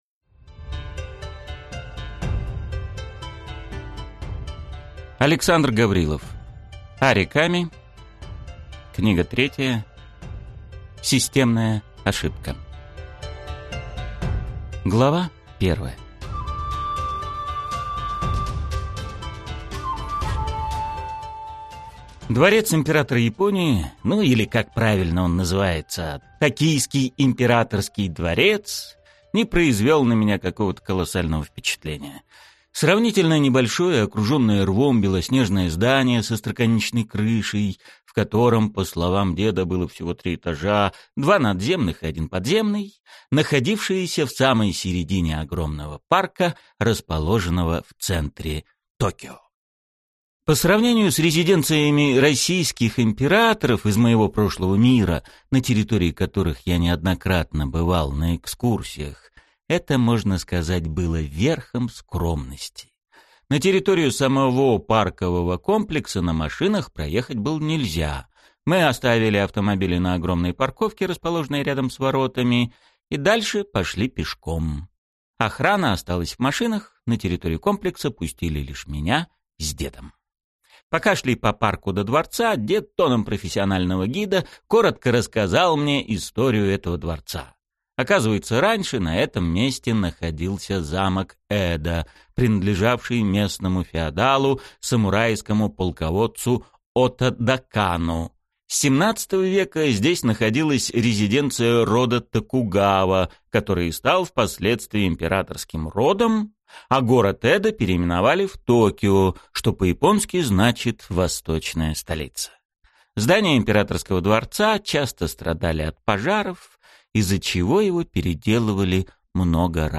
Аудиокнига Ари Ками. Книга 3. Системная ошибка | Библиотека аудиокниг